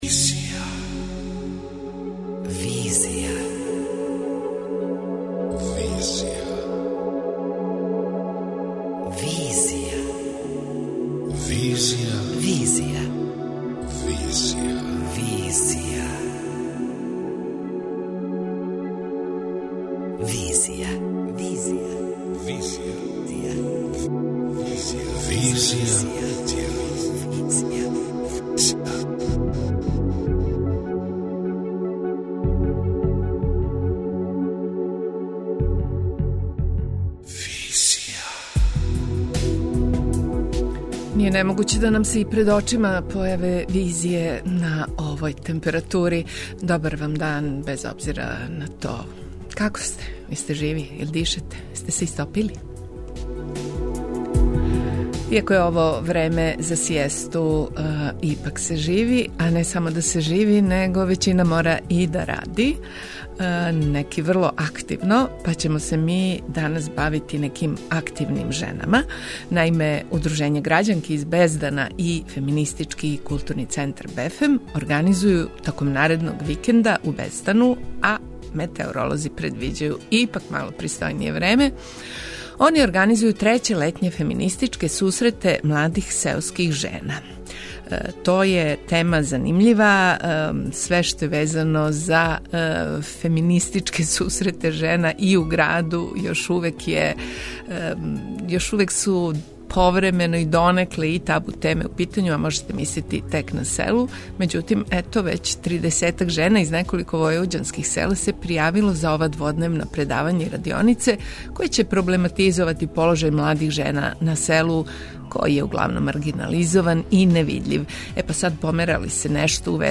преузми : 27.50 MB Визија Autor: Београд 202 Социо-културолошки магазин, који прати савремене друштвене феномене.